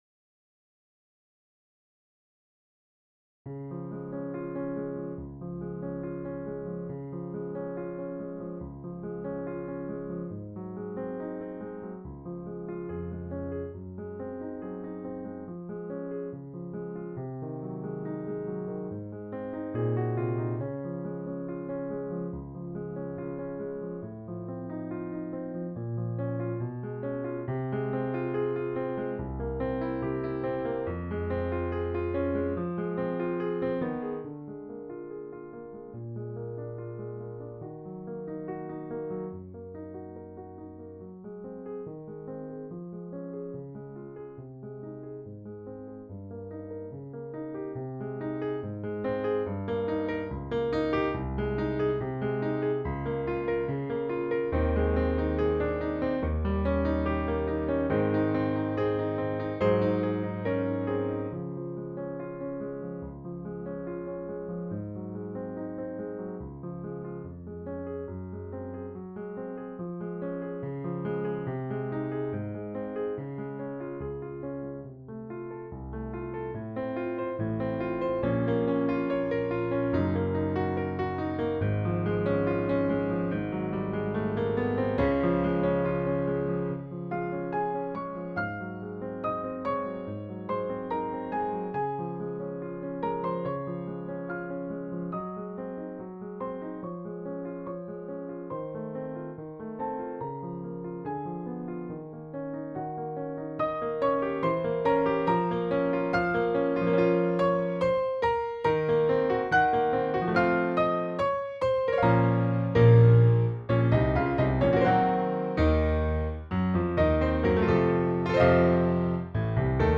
Voicing: French Horn and Piano